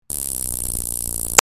Download Taser sound effect for free.
Taser